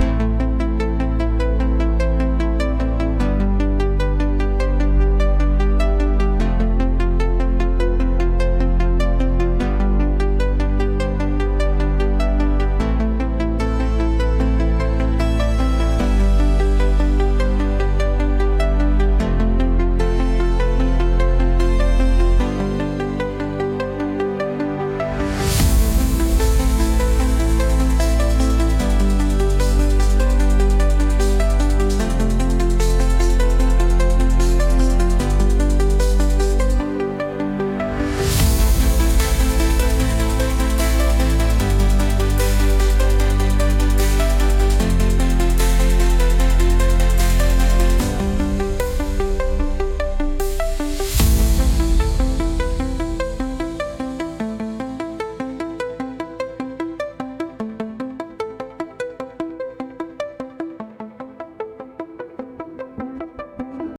Electronic, Cinematic
Epic, Dramatic
150 BPM